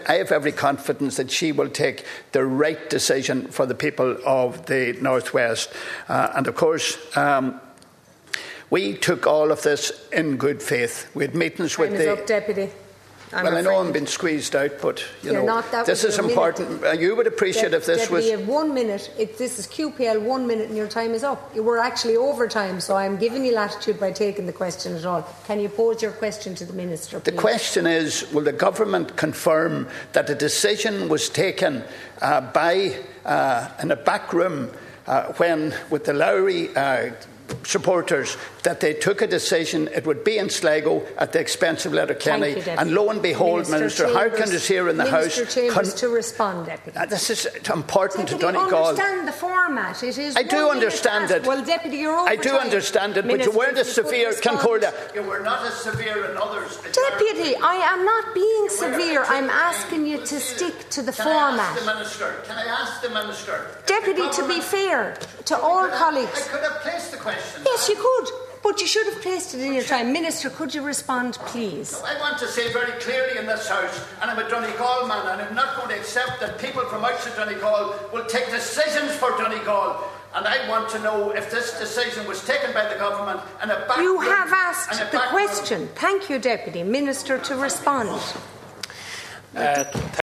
Deputy Gallagher, who clashed with the Ceann Comhairle about the amount of time he was given to ask his question, said clarity is needed………….